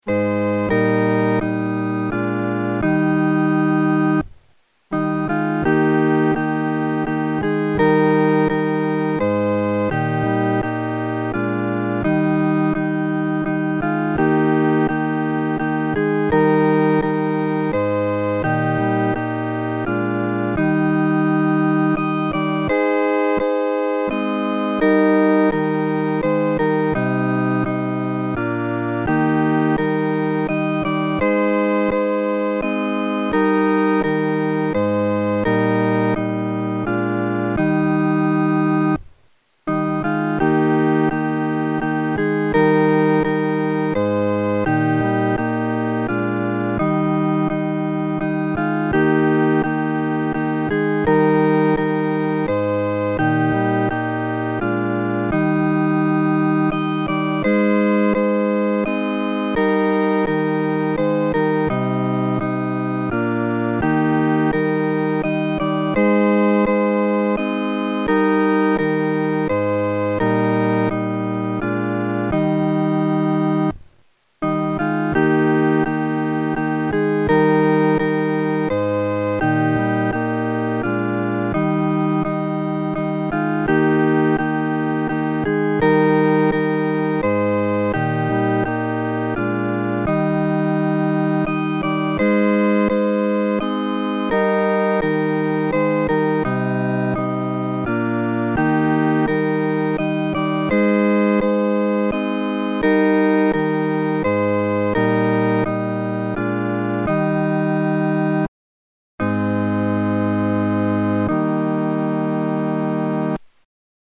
伴奏（四声）